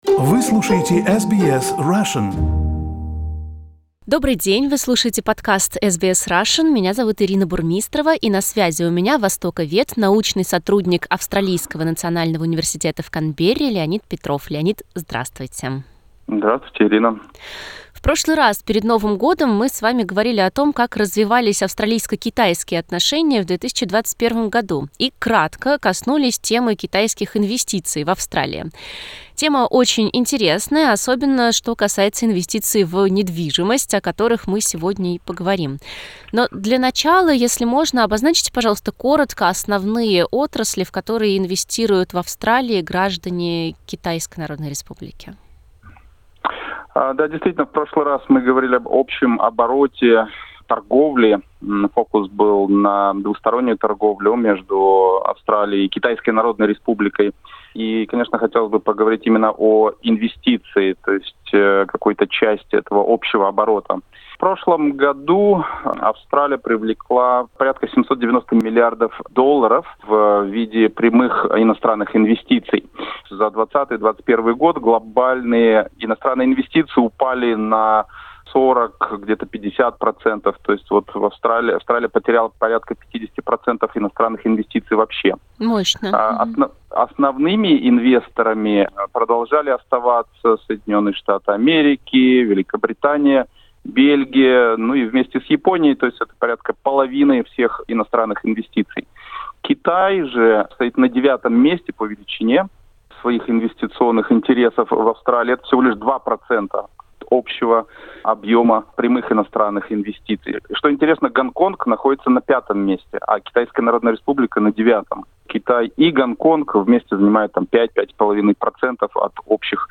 Гость подкаста